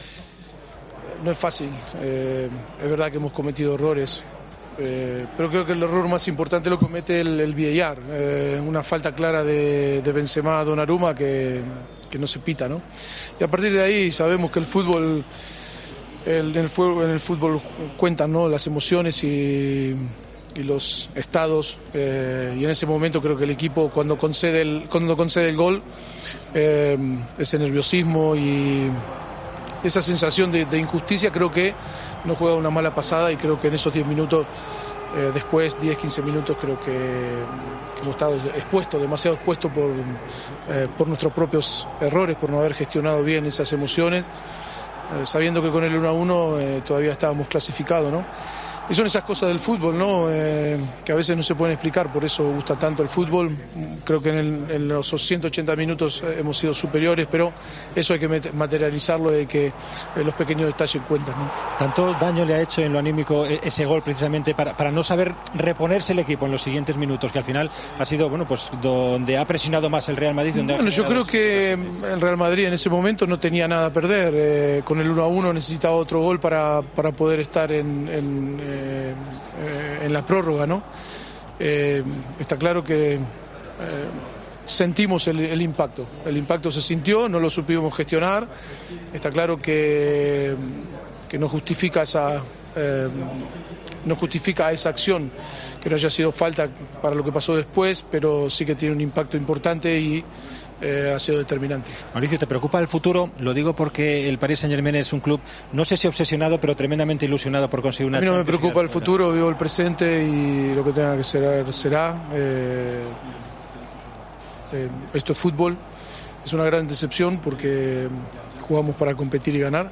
AUDIO: El entrenador del PSG analizó la derrota y eliminación ante el Real Madrid, criticando al árbitro por no pitar falta a Donnaruma en el 1-1.